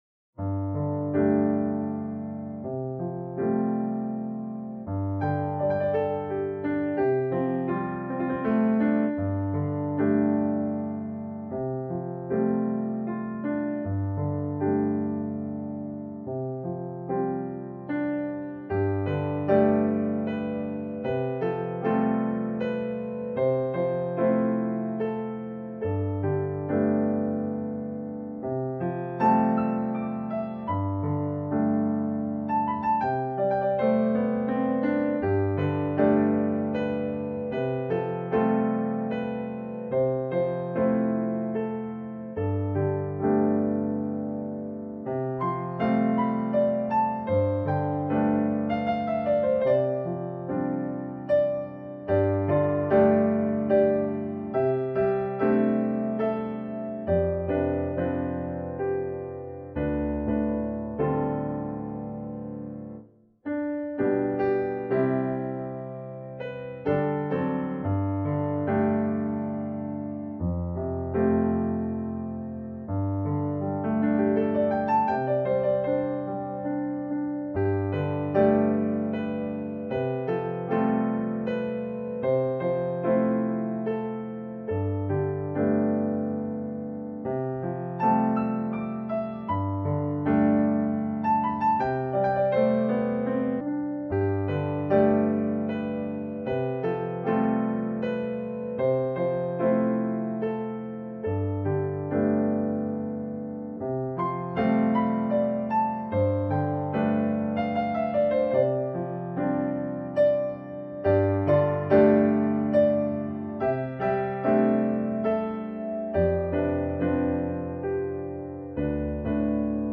for Piano, Vocals